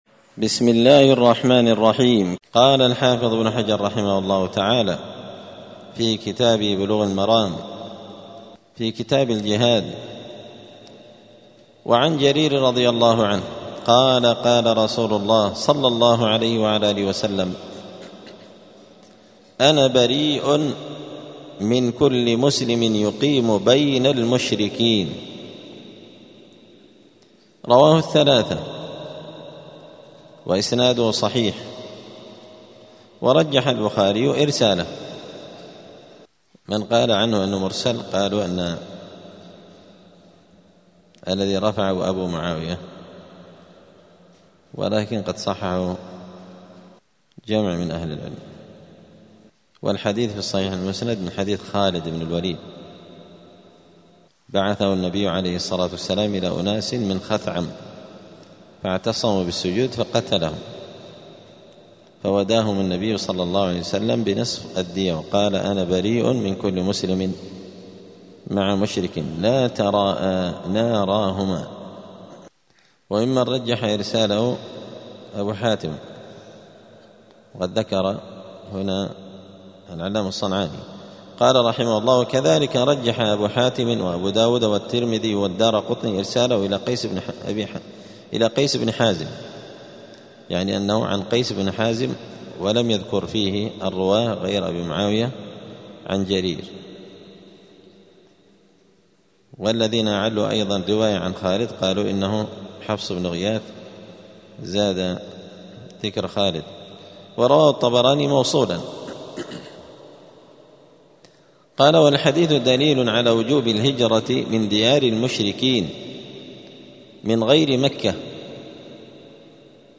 *الدرس الرابع (4) {باب الهجرة من دار الكفر}*